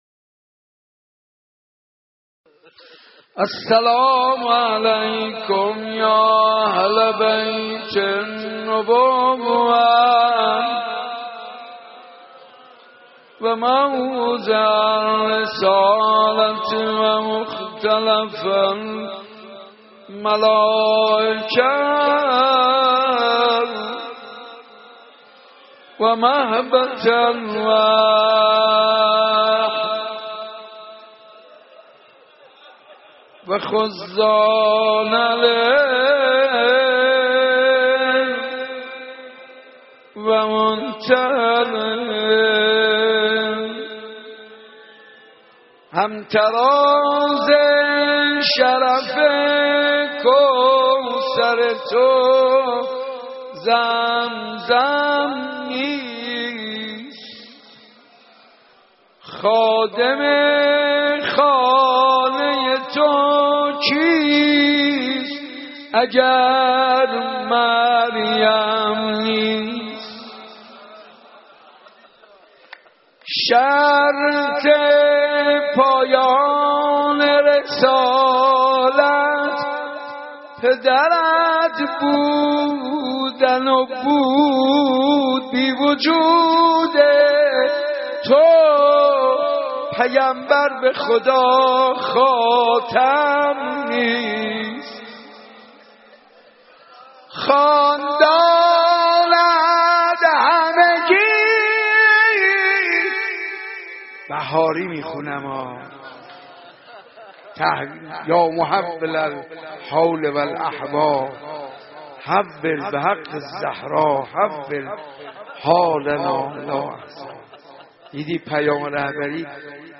مداحی فاطمیه